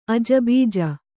♪ ajabīja